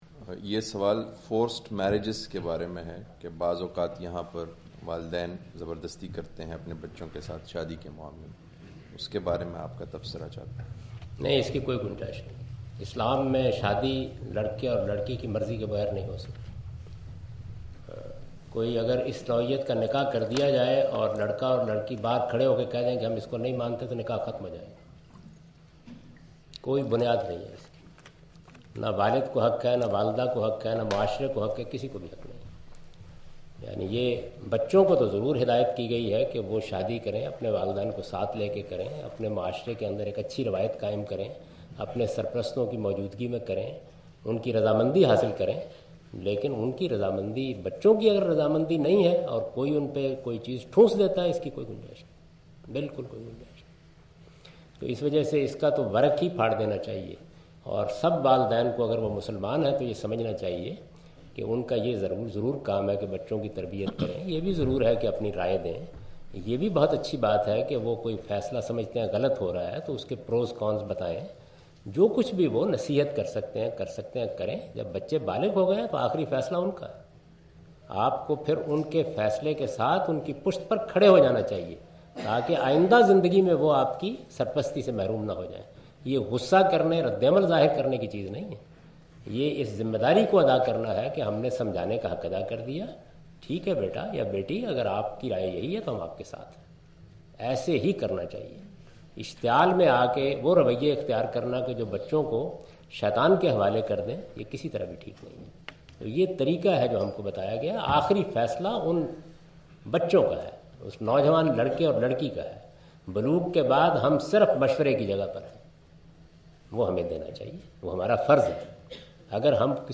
Javed Ahmad Ghamidi answer the question about "forced marriages " during his visit to Queen Mary University of London UK in March 13, 2016.